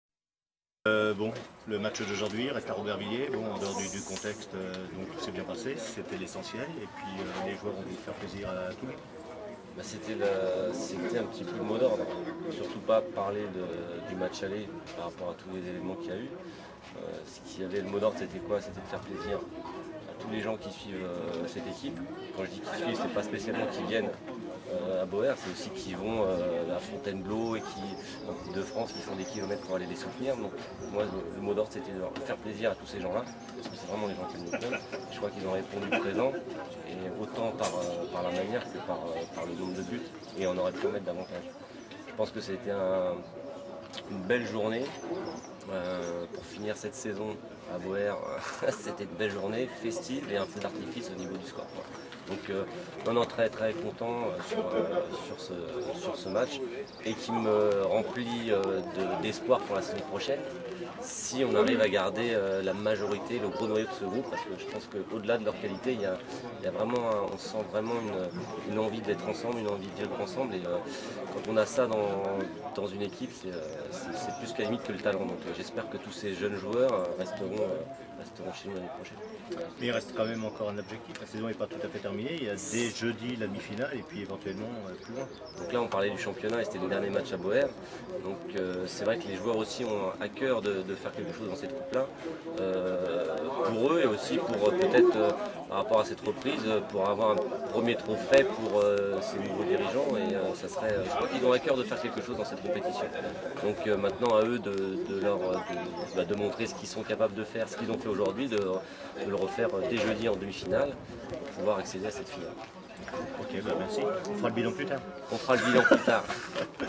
La nouveauté, la première interview audio, celle de l’entraîneur :